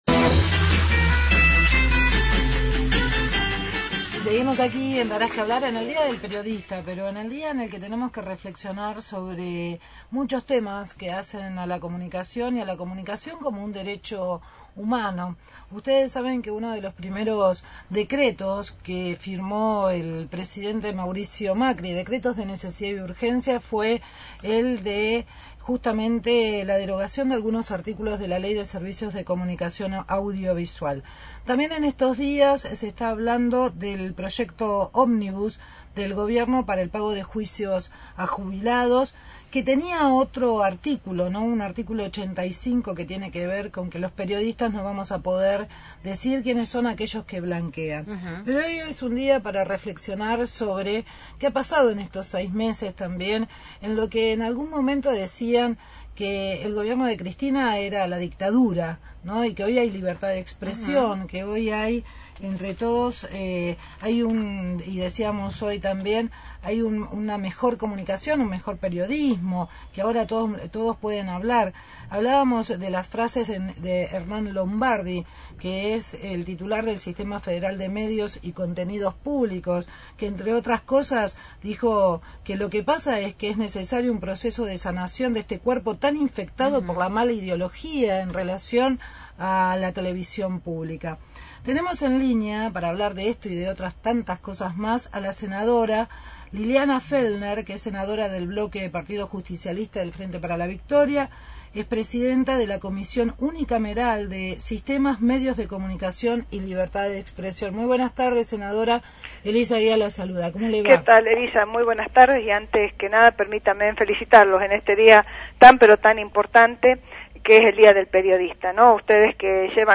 Entrevista a Liliana Fellner, senadora y Presidente de la Comisión Unicameral de Sistema, Medios de Comunicación y Libertad de Expresión sobre la libertad de expresión y los desafíos del periodismo en Argentina.